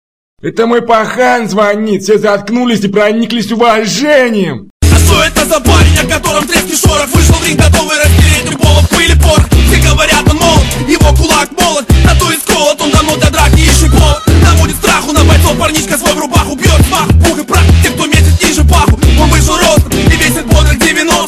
• Качество: 128, Stereo
Hiphop
голосовые